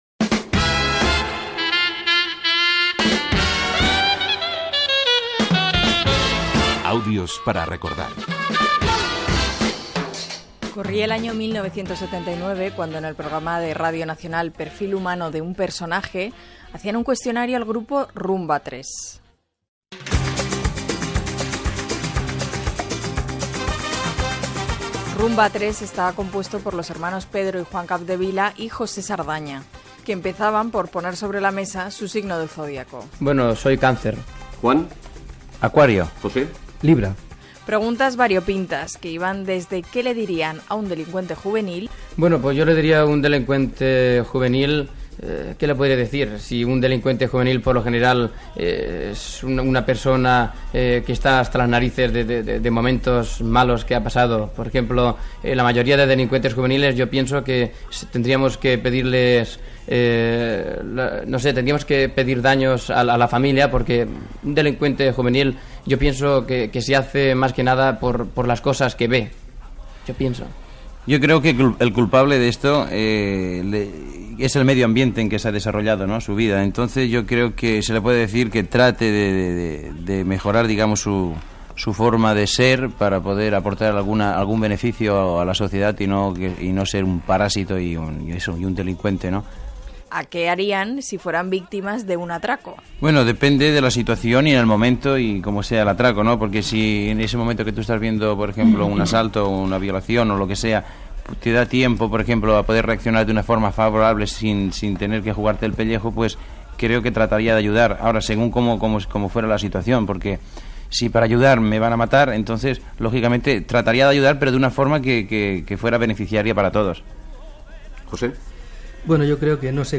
Careta del programa i fragments del programa "Perfil humano de un personaje" dedicat als tres integrants de Rumba 3
Entreteniment